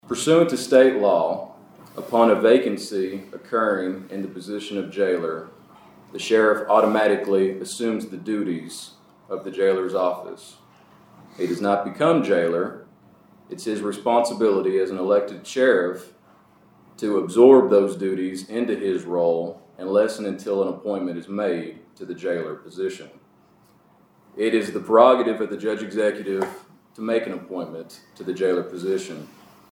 The Caldwell County Fiscal Court paused its Tuesday session to honor the late Jailer Willie Harper, who was remembered fondly for his dedication, financial stewardship, and ever-present smile following his courageous battle with cancer.